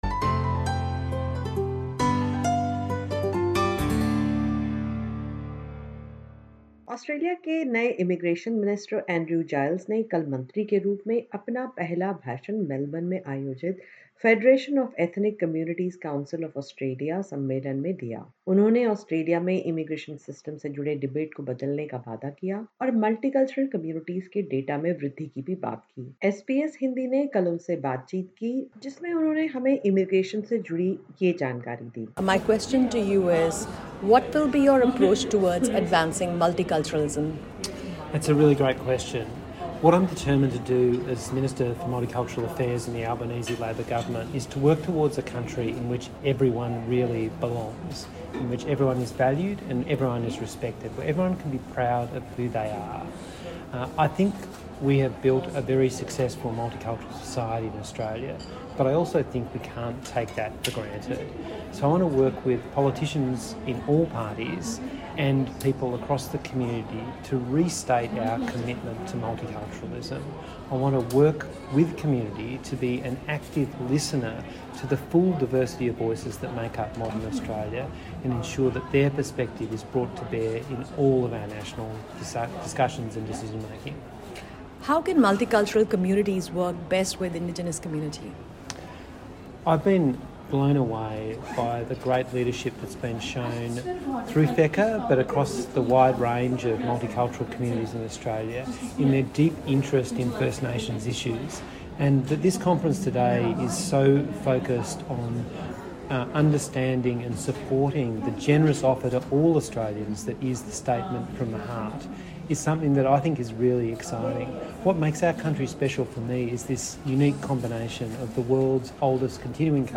Speaking exclusively to SBS Hindi, Minister Giles minced no words in saying that the new government was absolutely focused on dealing with the current visa backlog.